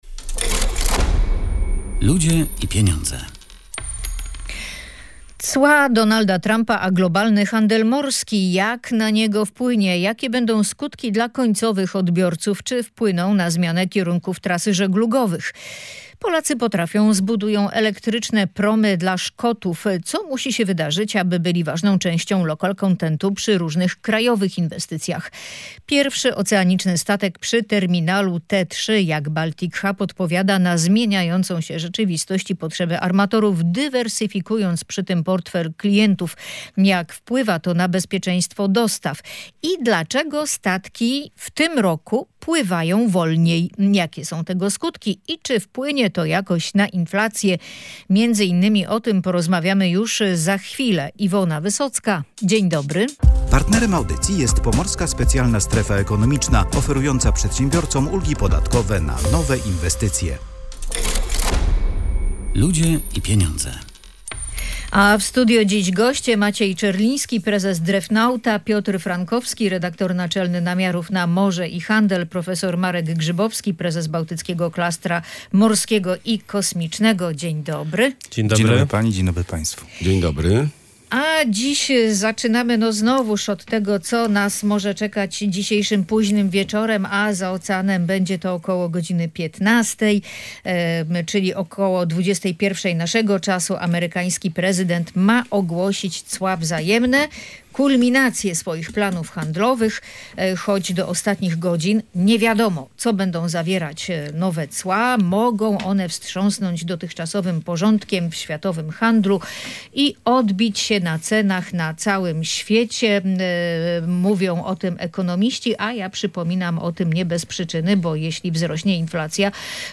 W środę o godz. 21:00 polskiego czasu prezydent USA Donald Trump ma ogłosić plan wprowadzania globalnych ceł. Jeśli to się stanie, skutkiem może być zmiana tras żeglugowych, sojuszy handlowych i braki pewnych towarów w sklepach – mówią goście audycji Ludzie i pieniądze.